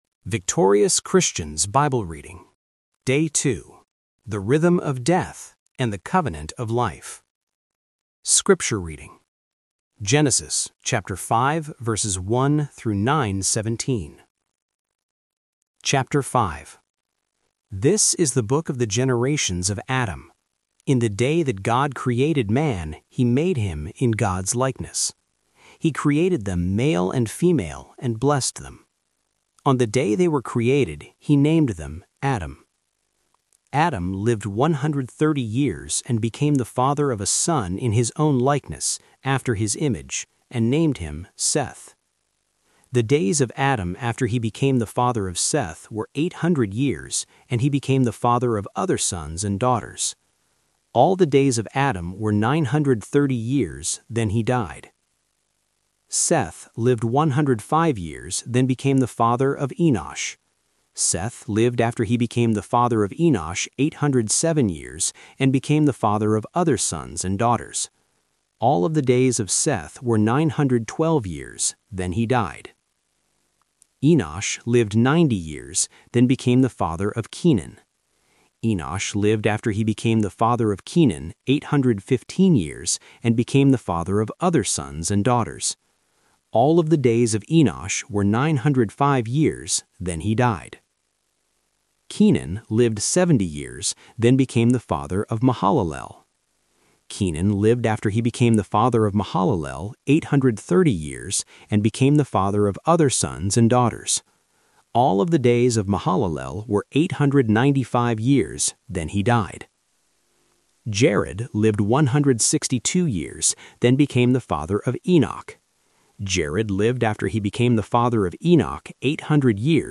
Day 2 — The Rhythm of Death and the Covenant of Life - Bible Reading - Victorious Christians